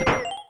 Add sound effects!
socket.ogg